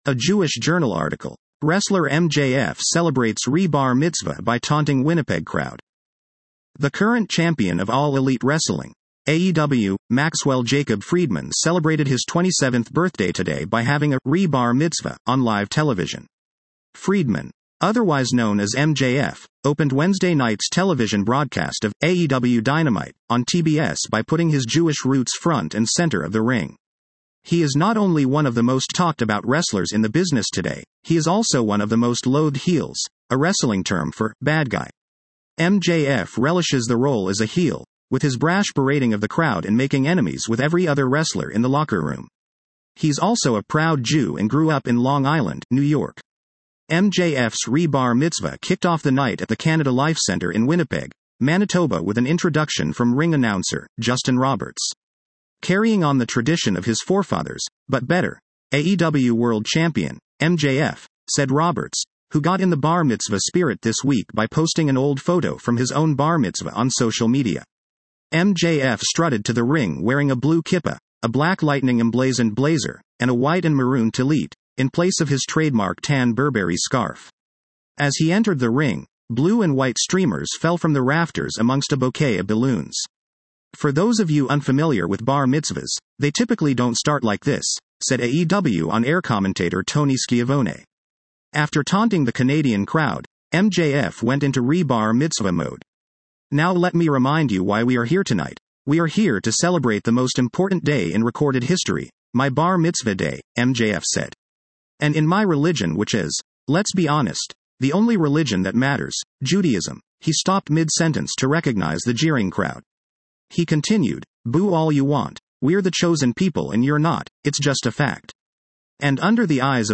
The current champion of All Elite Wrestling (AEW) Maxwell Jacob Friedman celebrated his 27th birthday today by having a “re-Bar Mitzvah” on live television.
MJF’s re-Bar Mitzvah kicked off the night at the Canada Life Centre in Winnipeg, Manitoba with an introduction from ring announcer, Justin Roberts.
At that moment, “Havah Nagila” played in the arena and nine people came rushing down the aisle to the ring, dressed in suits and carrying a felt-cushioned chair.
The crowd in the arena went nuts.